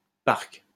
Parc station (French pronunciation: [paʁk]